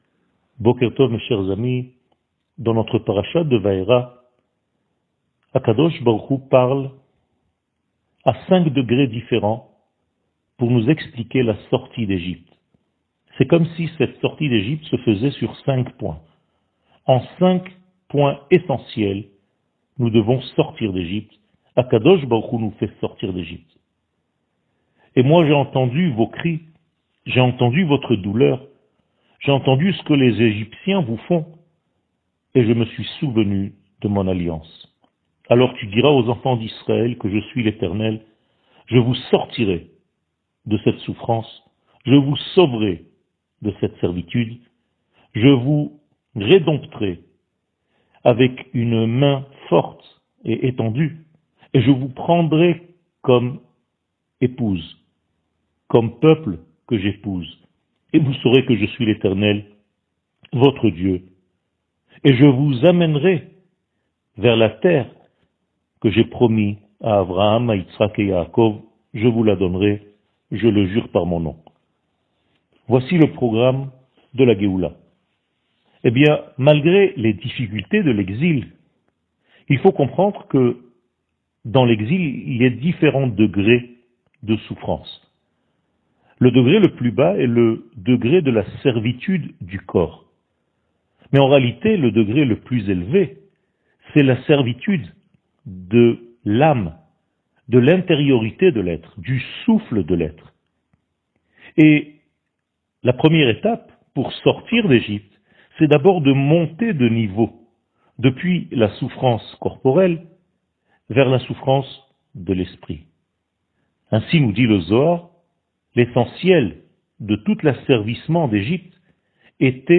שיעור מ 04 ינואר 2022